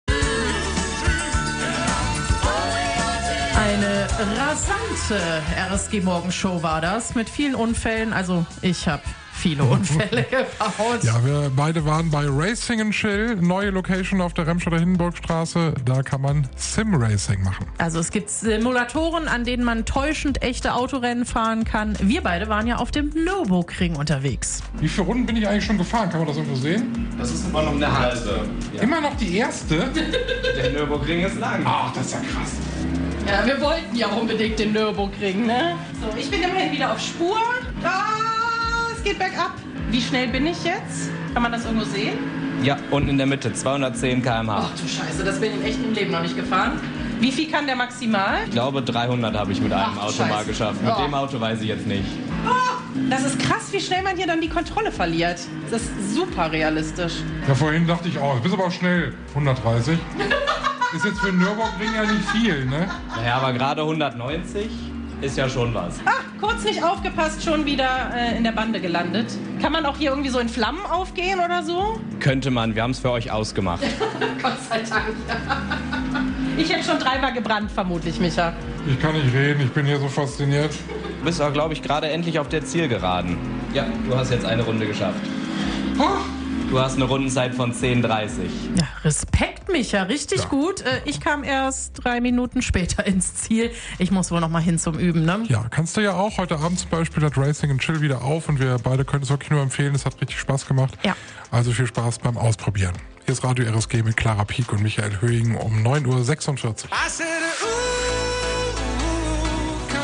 Einen Hauch von Formel 1 ab sofort in Remscheid. Unsere Moderatoren haben die E-Racing Simulatoren für euch getestet.